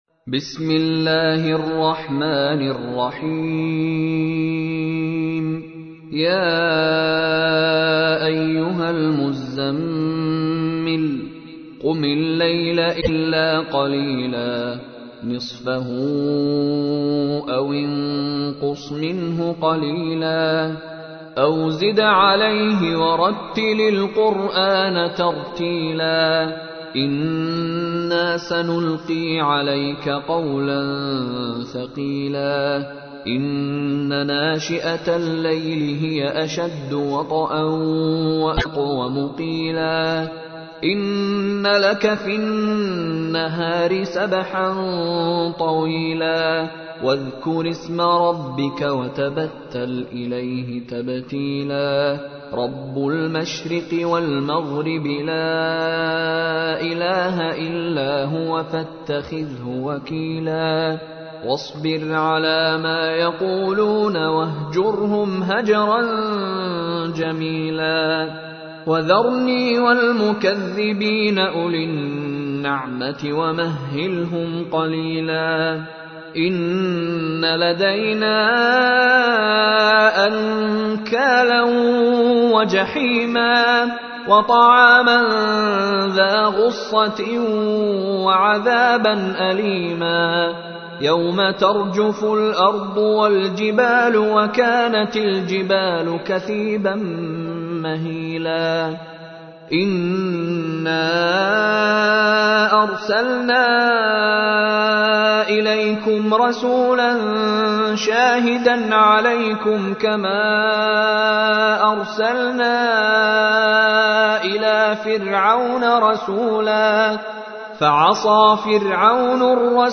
تحميل : 73. سورة المزمل / القارئ مشاري راشد العفاسي / القرآن الكريم / موقع يا حسين